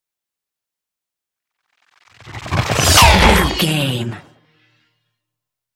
Whoosh metal sword
Sound Effects
whoosh